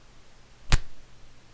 战斗的声音 " 巴掌2（干净）
描述：录制在Zoom H4n上。 张开的手掌对人脸完全孤立的令人满意的影响。
Tag: 裂纹 拳头 拍击 咂嘴 斗争 战斗 冲头 人类 POW 现场录音 打击 冲击